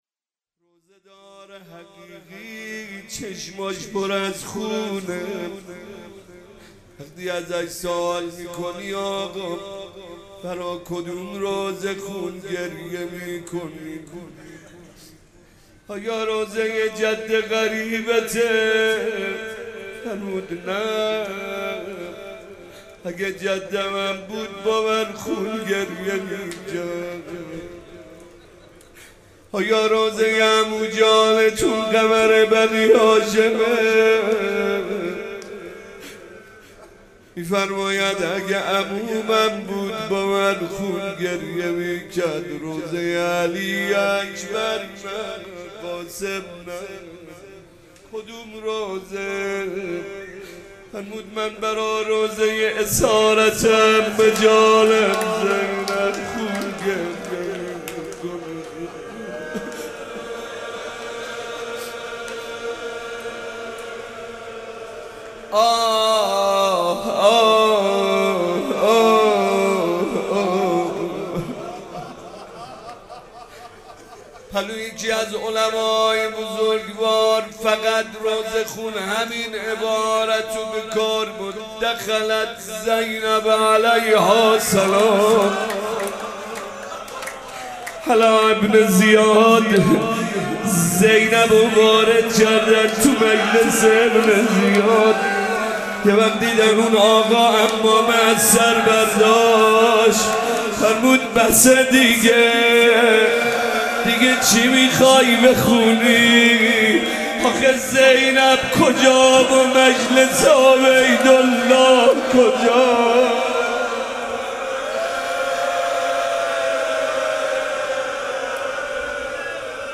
مداحی های شب بیست و ششم ماه مبارک رمضان در هیئت مکتب الزهرا(س)
روضه شب بیست و ششم